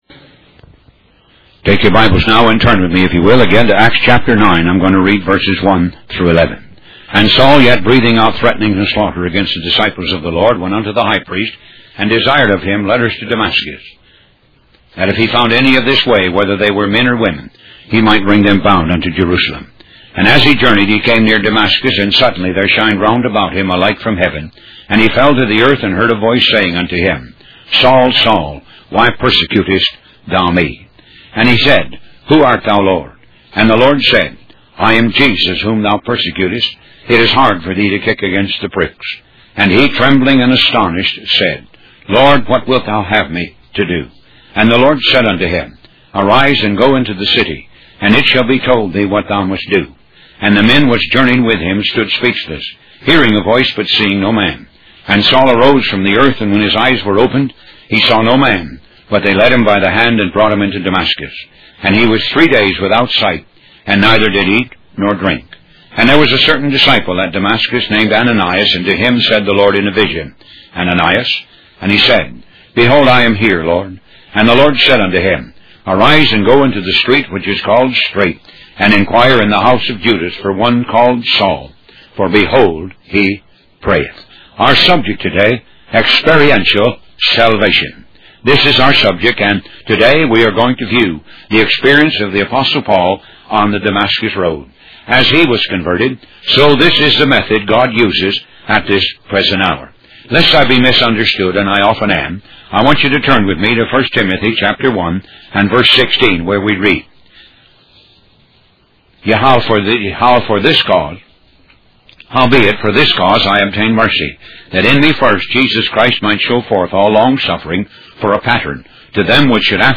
Talk Show Episode, Audio Podcast, Moga - Mercies of God Association and Personal Experimental Redemption on , show guests , about Personal Experimental Redemption, categorized as Health & Lifestyle,History,Love & Relationships,Philosophy,Psychology,Christianity,Inspirational,Motivational,Society and Culture